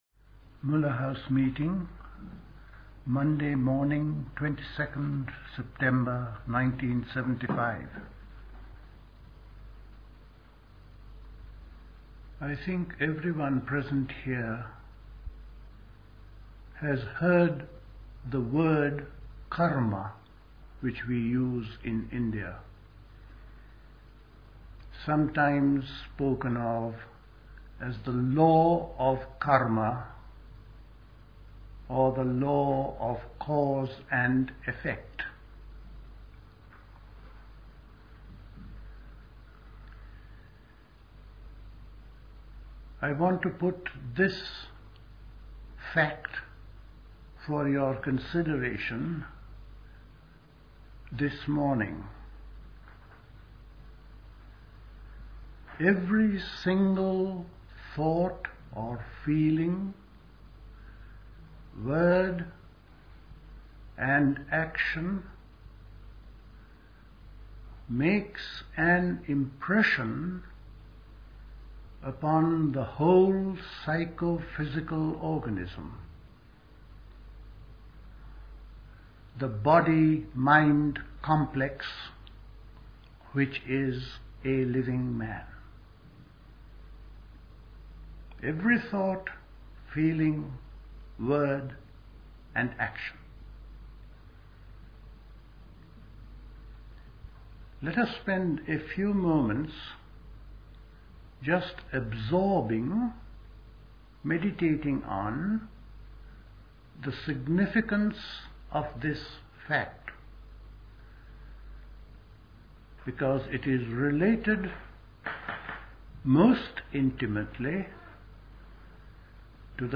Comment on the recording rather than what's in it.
Recorded at the 1975 Elmau Autumn School.